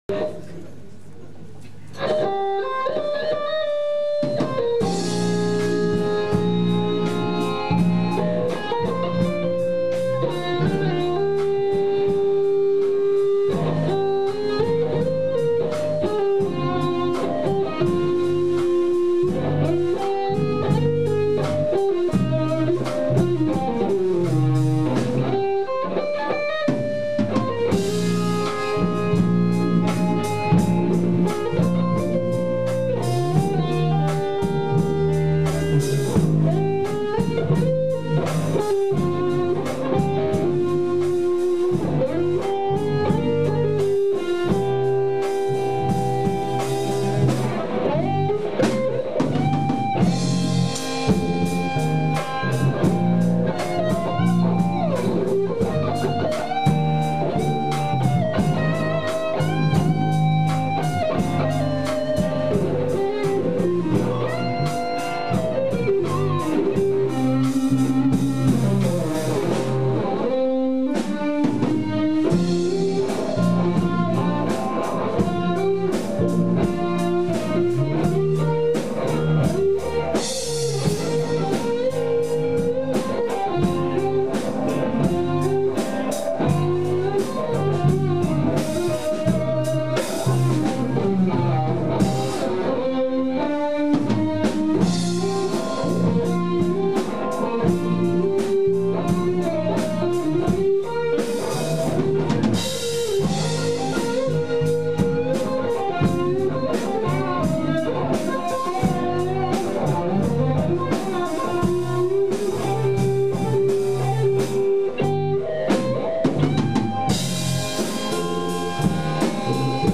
全く違うので、今回はキーボードがありませんでした。
比較的アナログ的な音が出せていると思います。
ＥＭＧ－ＳＡシングルコイル、フロントポジションで弾いています。
チョーキングが上がりきっていない所があるのが悲しい所です。
テンポも０９年のジャストのテンポより１.数倍速でして
で、ちと、違うアレンジです。
今回かなり深めにコーラスかけましたが２台アンプが欲しいと思いました。